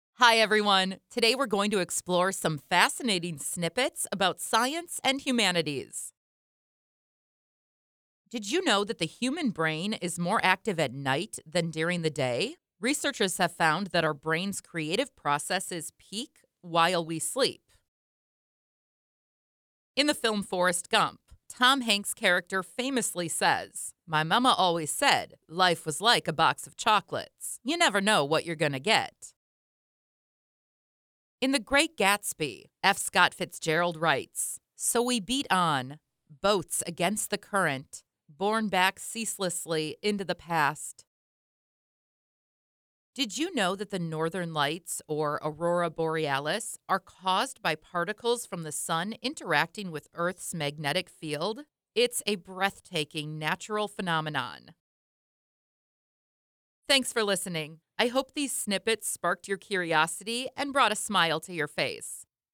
Where voices are not provided, as is typically the case for open source models, we use voices clips from professional voice actors as source files for generating speech.